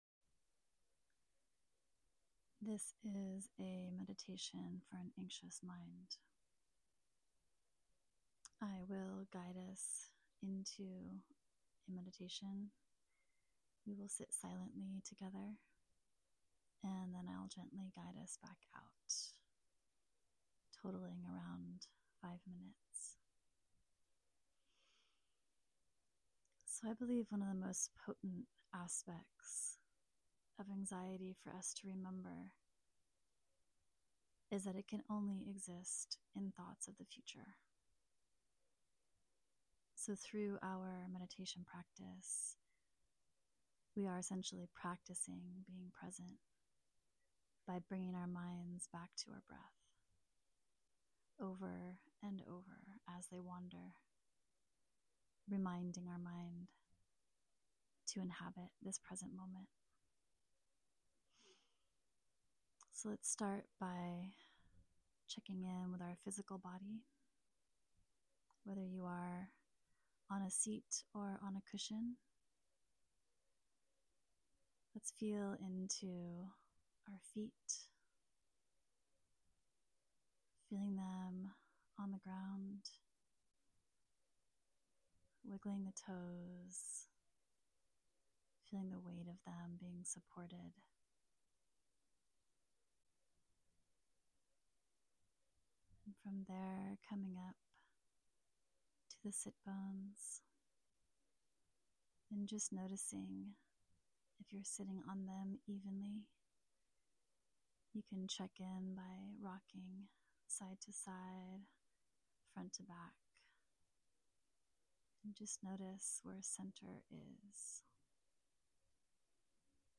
Meditation-for-Anxiety.mp3